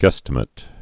(gĕstə-mĭt)